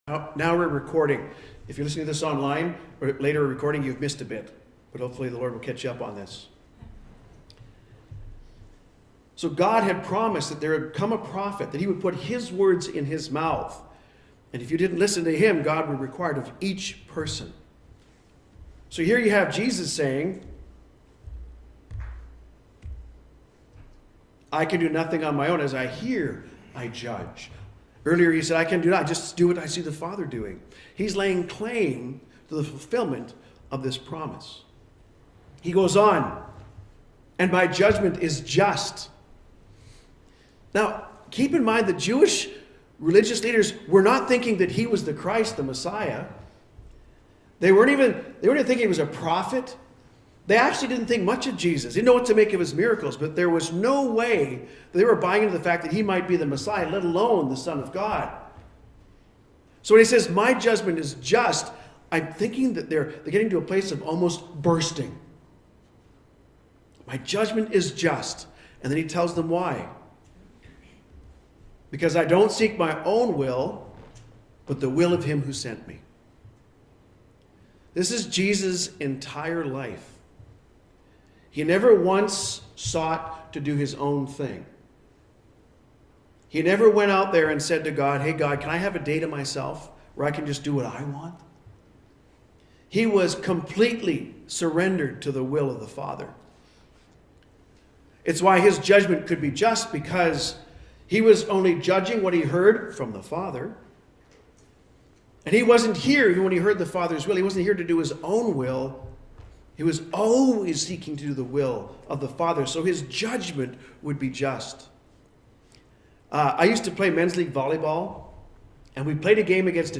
(Ps: we apologize for the recording got started a bit late)